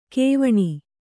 ♪ kēvaṇi